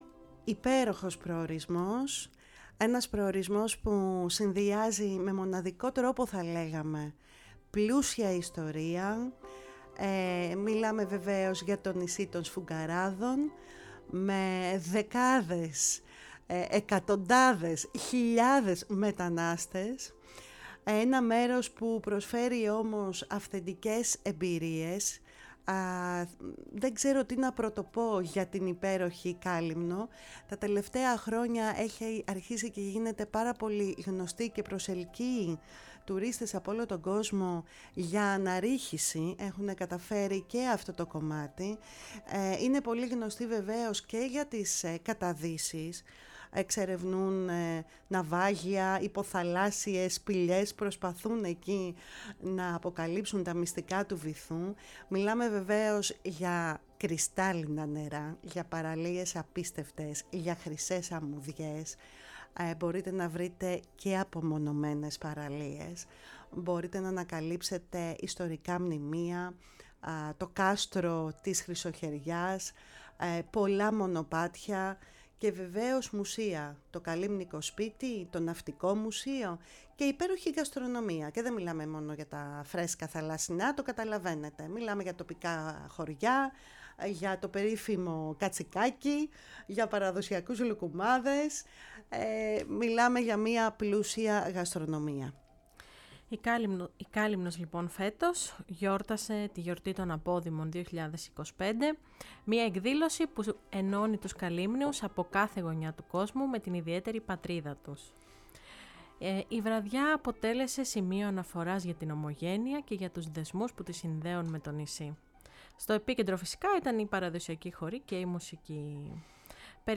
Με αφορμή τη “Γιορτή των Αποδήμων” που διοργάνωσε ο Δήμος Καλύμνου με τη συμμετοχή πολλών Καλύμνιων αποδήμων, η εκπομπή φιλοξένησε τον δήμαρχο κ. Ιωάννη Μαστροκούκο